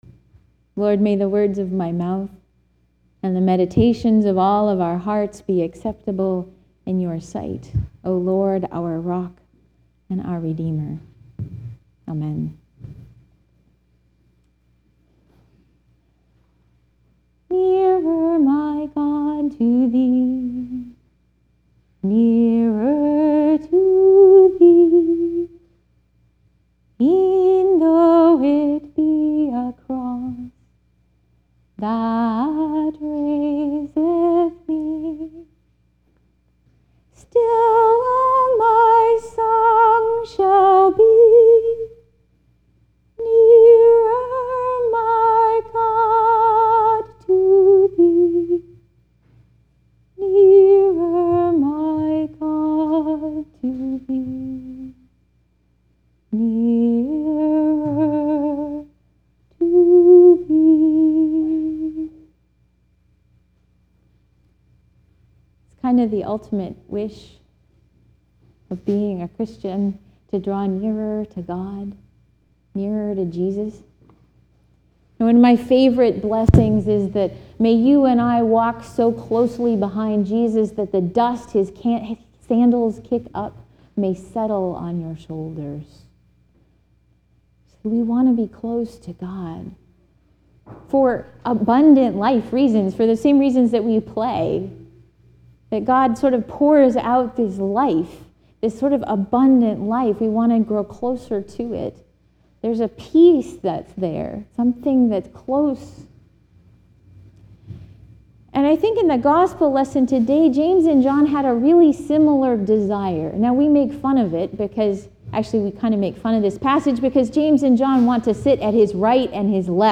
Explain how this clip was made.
*Sermon preached with no manuscript.*